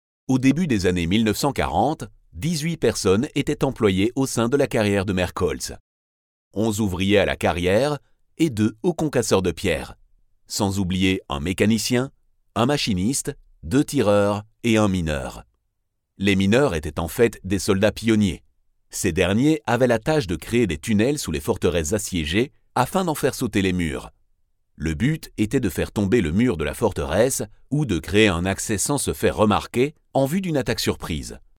French Male Voice Over Artist
Confident, Corporate, Natural, Reassuring, Warm
Audio equipment: StudioBricks booth, RME Babyface interface, CAD EQuitek E100S mic